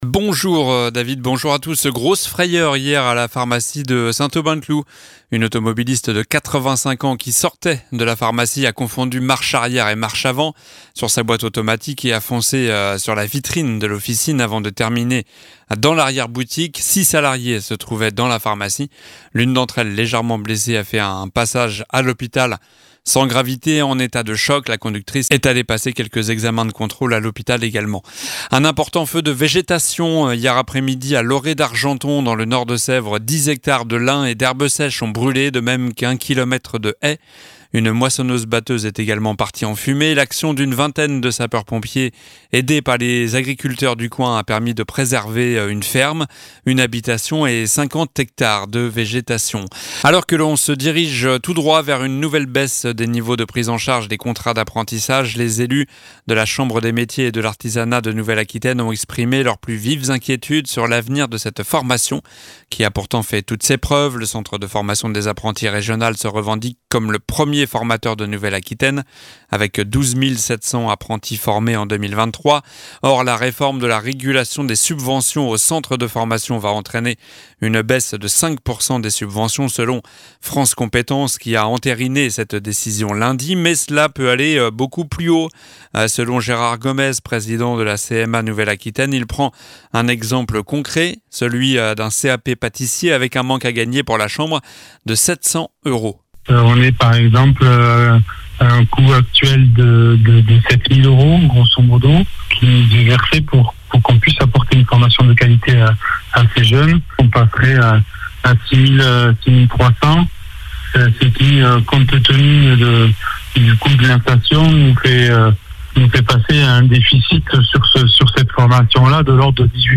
Journal du mercredi 19 juillet (midi)